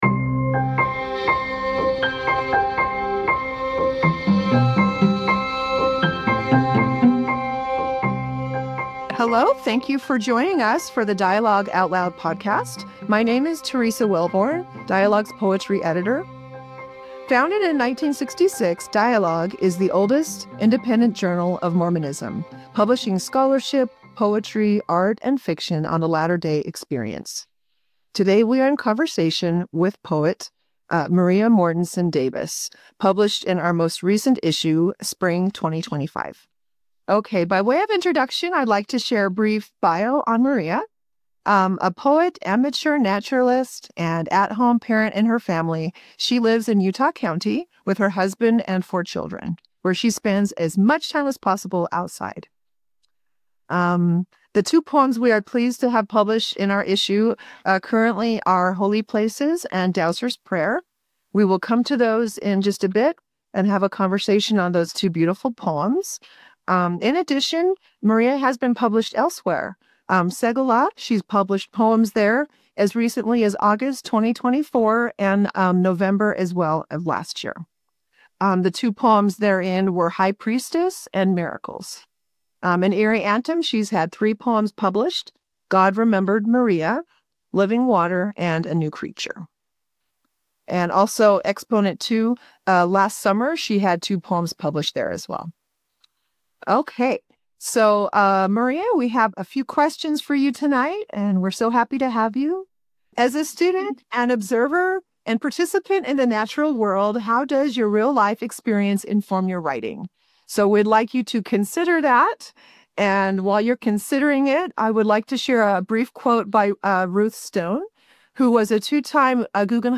Poetry of the Natural World: A Conversation